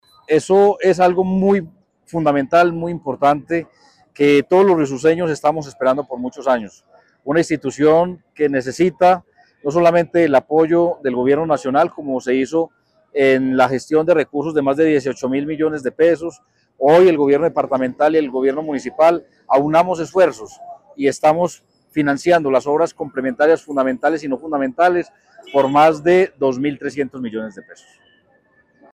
Abel David Jaramillo Largo, alcalde de Riosucio
Alcalde-de-Riosucio-Abel-David-Jaramillo-recursos-IE-Normal-Sagrado-Corazon.mp3